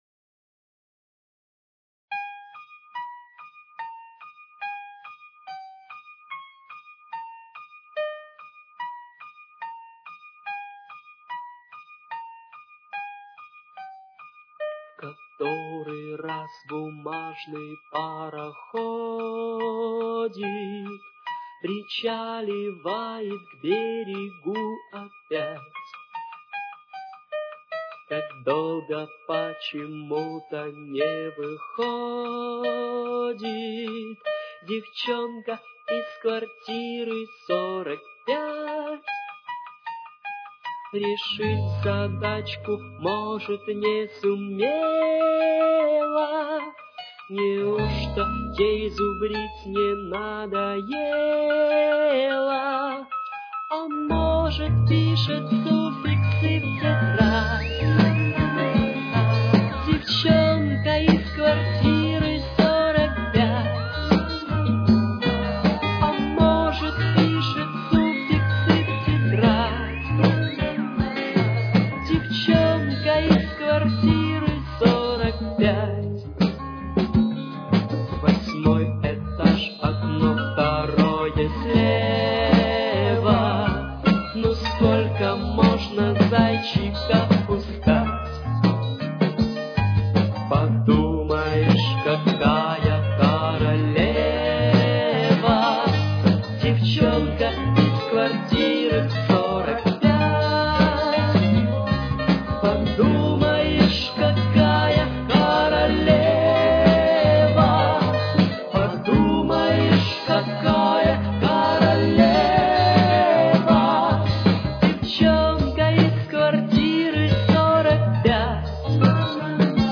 с очень низким качеством (16 – 32 кБит/с)
Соль минор. Темп: 80.